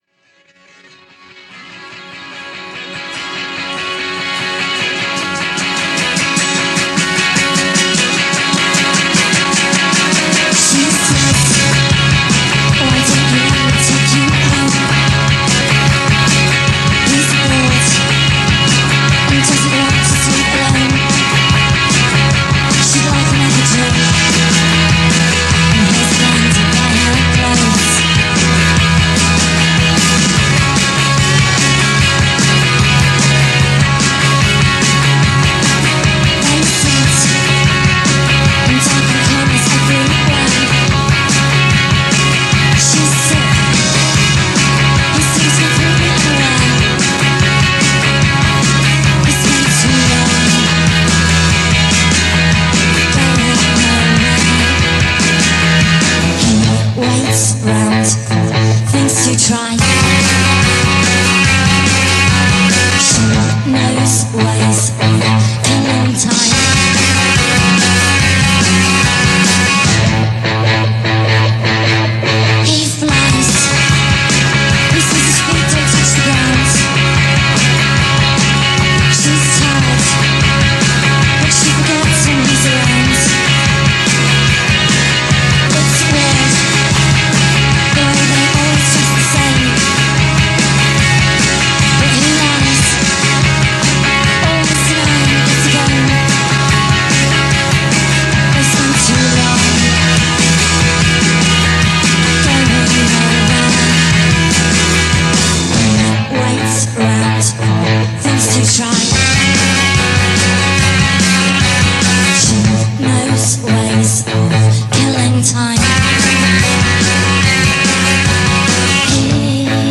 Short-lived Indie Band from London
whose voice was hypnotic
guitarist
turned out some nicely intense music